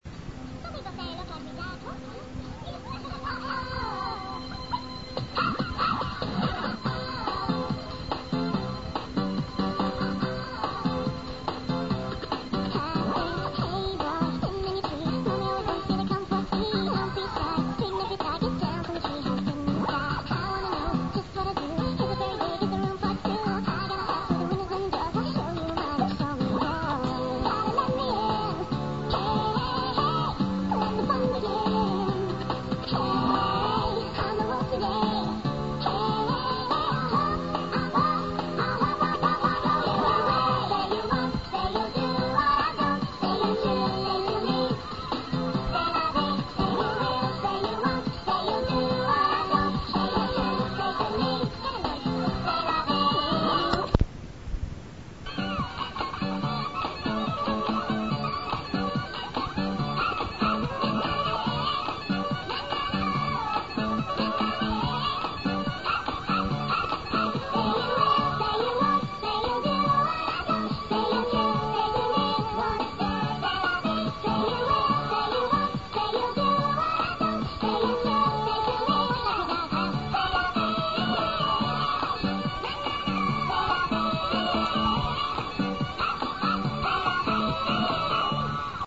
Know of a song you want to hear chipmunk-ized?